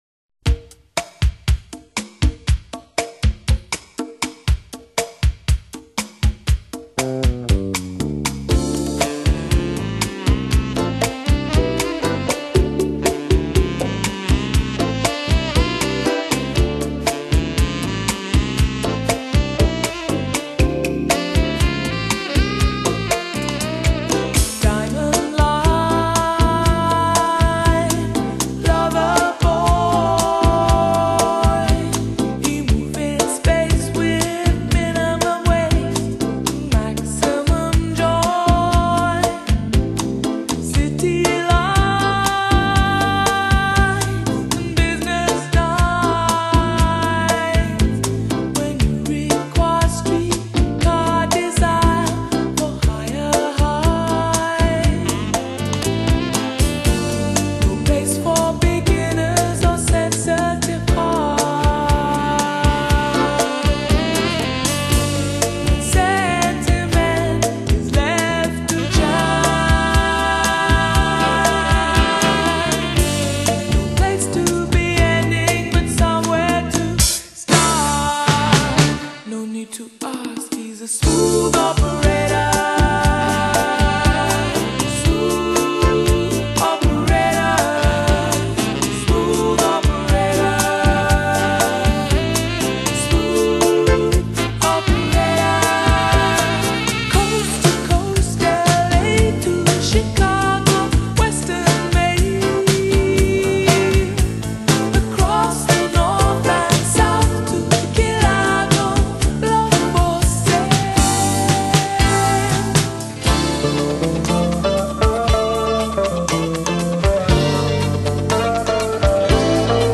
她的音乐是温暖的，柔软的，可以让耳朵，灵魂抚慰的。